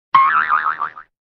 bounce2.ogg